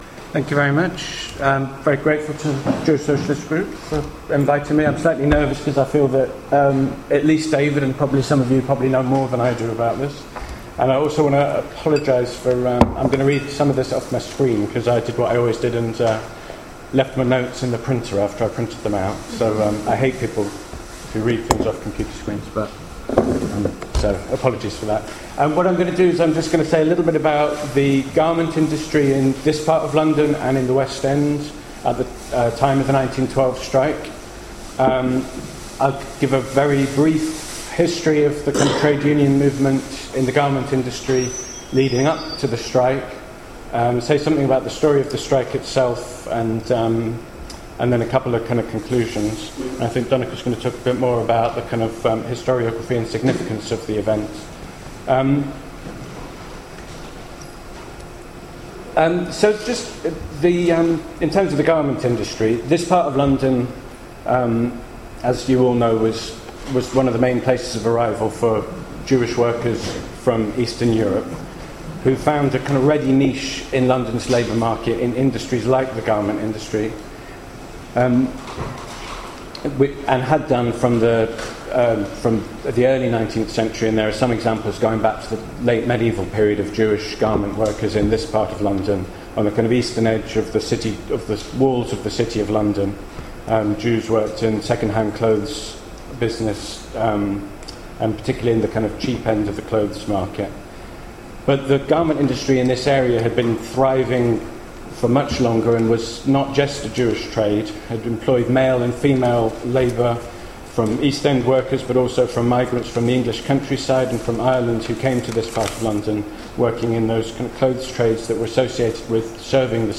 Commemorating the strikes of 1912 meetingOn 23 May, the Jewish Socialists’ Group held a meeting in the library of the Bishopsgate Institute to discuss the history and continuing relevance of the 1912 tailor’s strike, inspired by Rudolf Rocker. The meeting covered the history of unionism in the East End, including the Docks, the history of the rag trade and sweatshops, the strike in 1912 and what it can teach the contemporary trade union movement.